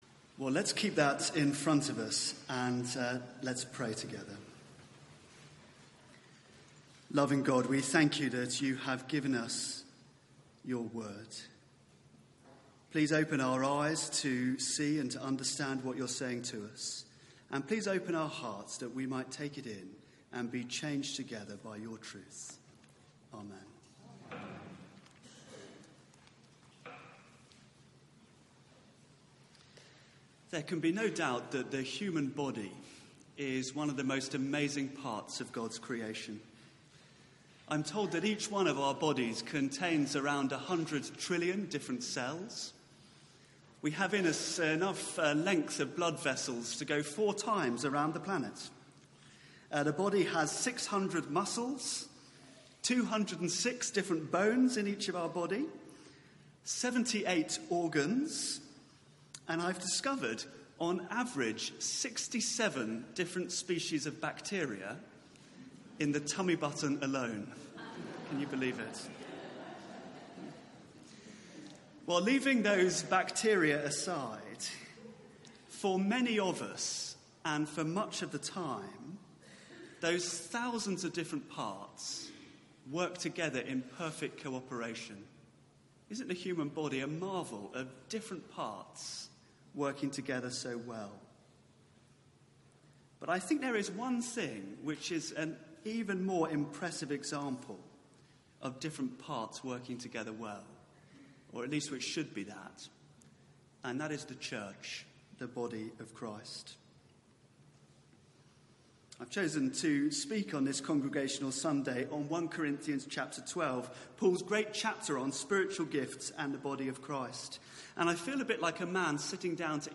Media for 9:15am Service on Sun 30th Sep 2018 09:15 Speaker
Congregational Sunday Sermon (11:00 Service) Search the media library There are recordings here going back several years.